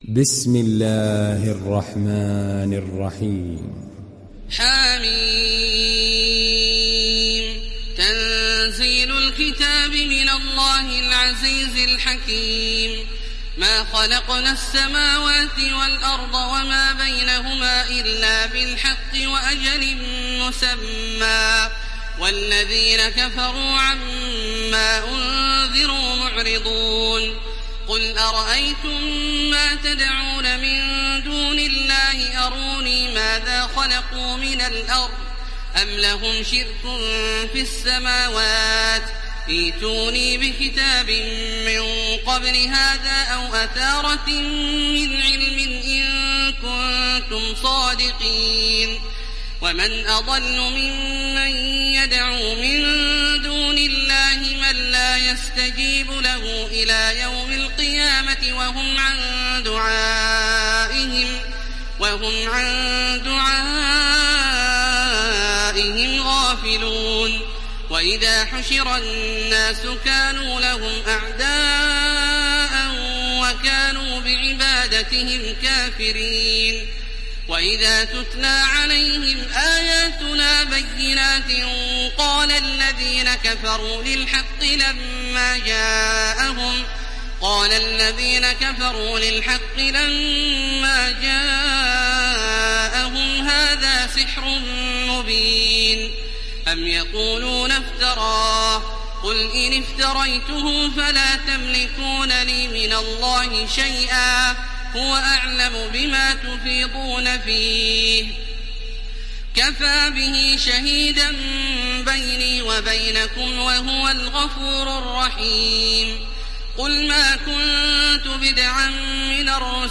Download Surat Al Ahqaf Taraweeh Makkah 1430